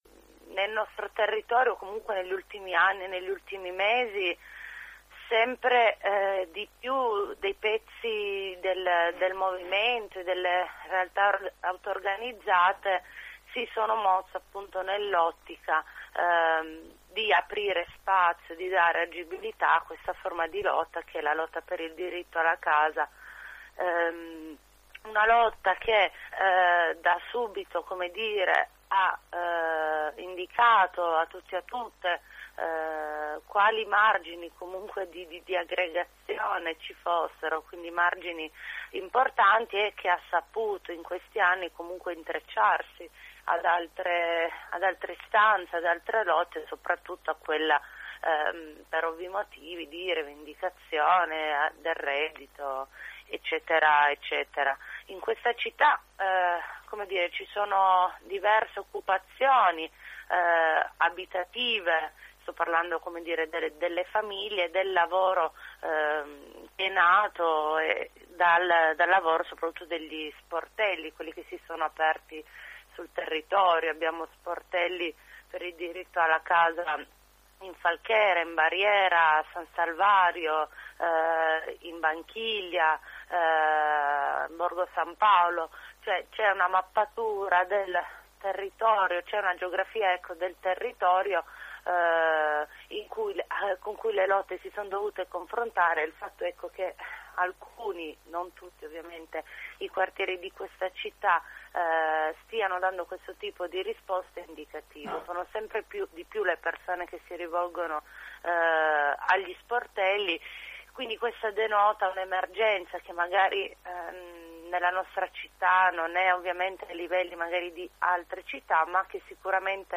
Ascolta la presentazione della mobilitazione nella diretta effettuata questa mattina